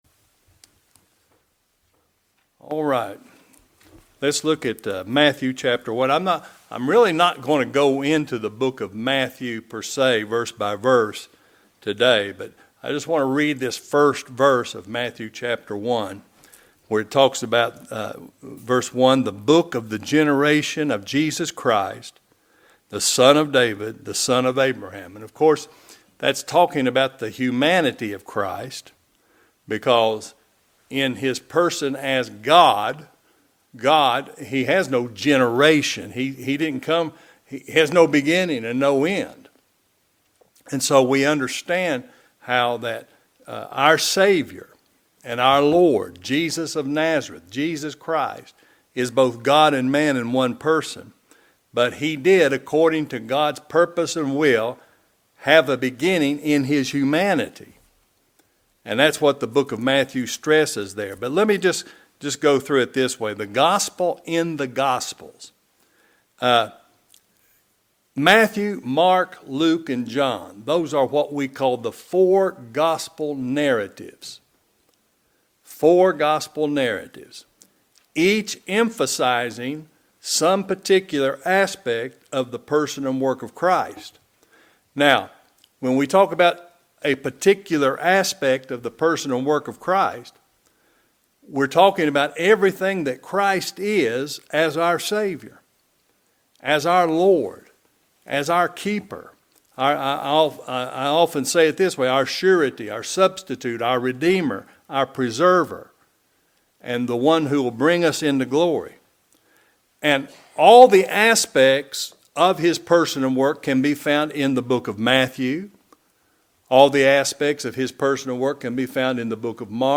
The Gospel in the Gospels | SermonAudio Broadcaster is Live View the Live Stream Share this sermon Disabled by adblocker Copy URL Copied!